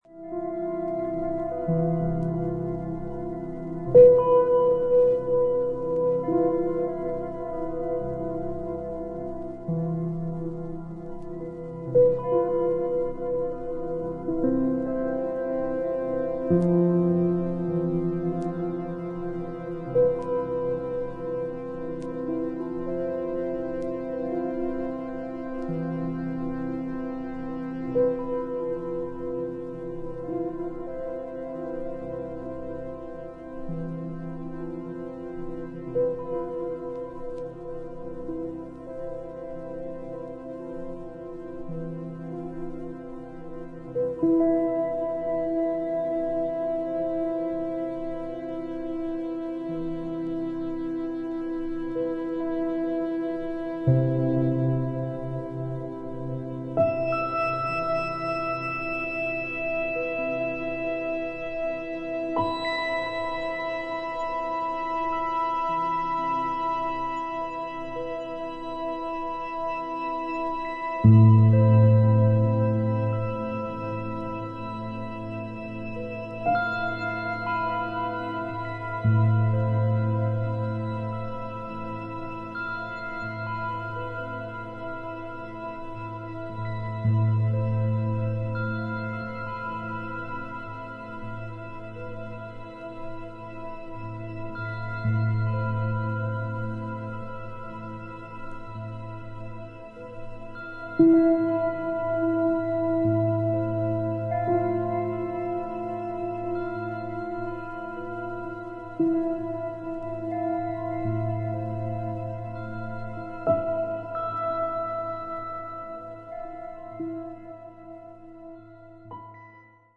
ピアノやヴァイオリン、ヴィオラ、ムーグギター等、穏やかで温かみのある楽器の音色をベースに